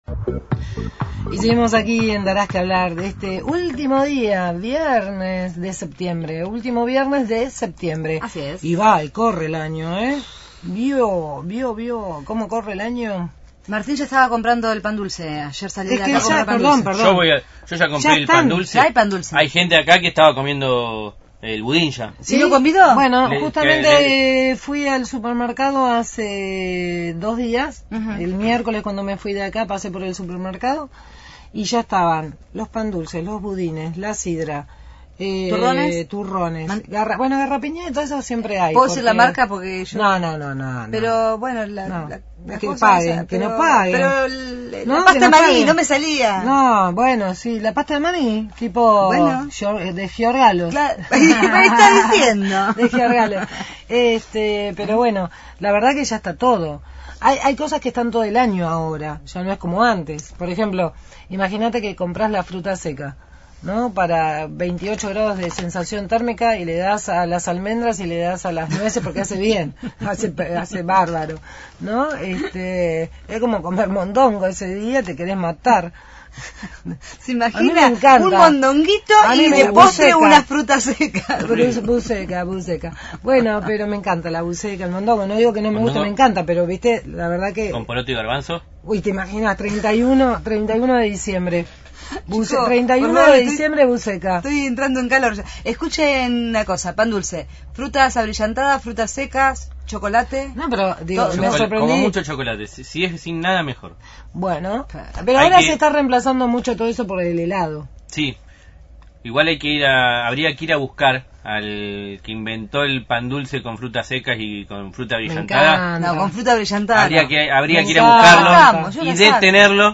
desde el RALLYDAD, rally sobre silla de ruedas para luchar por la accesibilidad en las escuelas.